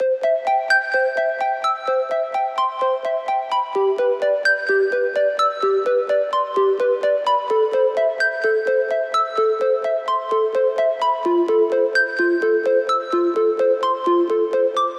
Tag: 128 bpm Dance Loops Harp Loops 2.52 MB wav Key : C